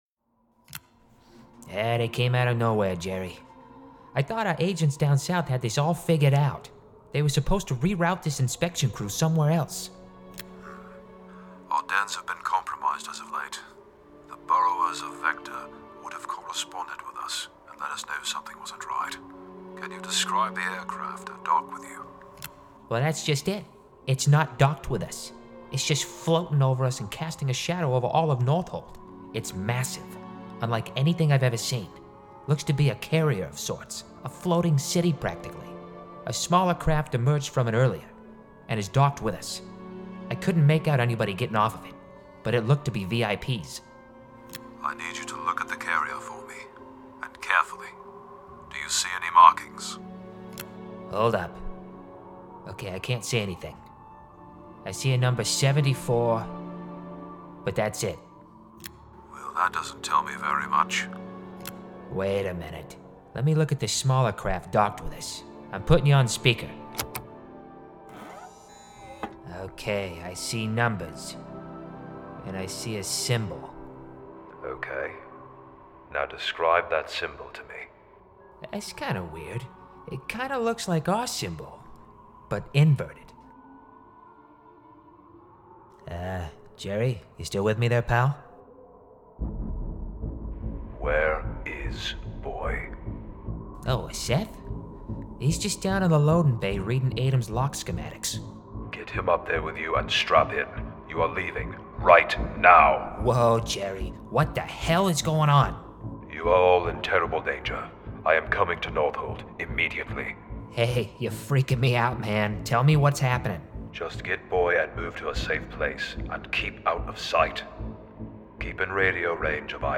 Character Voice Demos
SCE1S6 DEMO Two-Character Voices
SCE1S6-DEMO-Two-Character-Voices.mp3